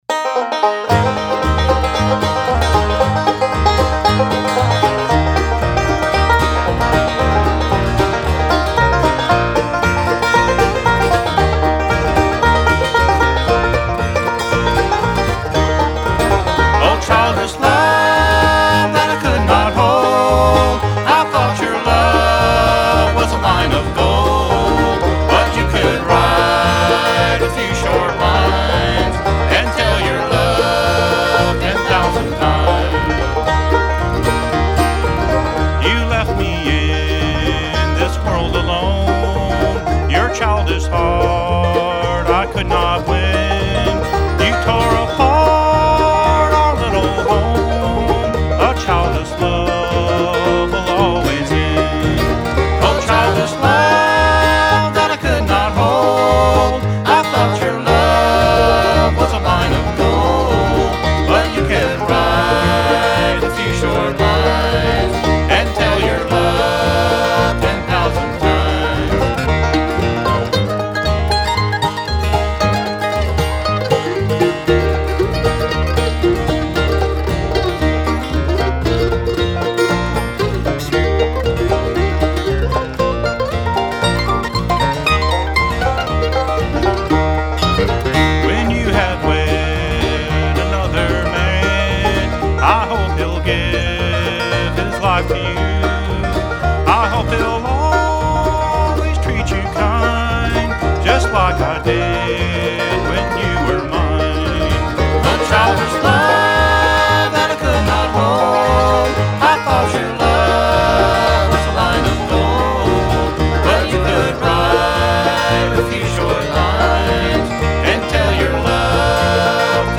Recorded at Grierlandia in summer 2016.